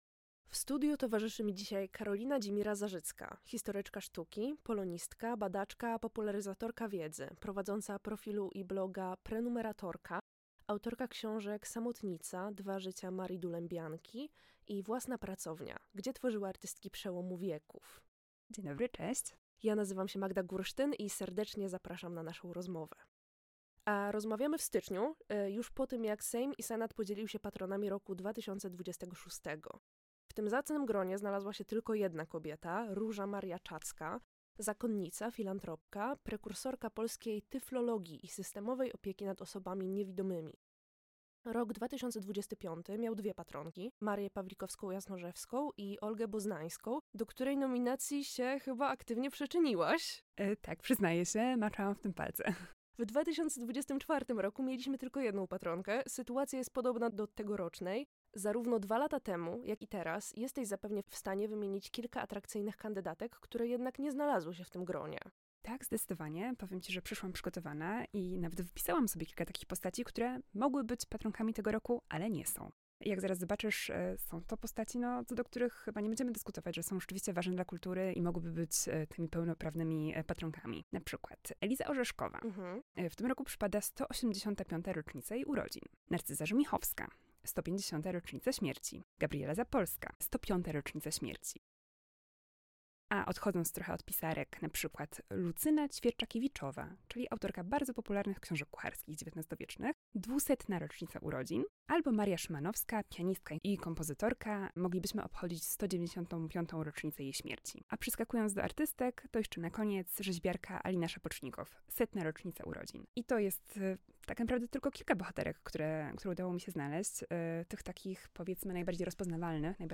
Dlaczego brakuje nam Patronek Roku 2026? Rozmowa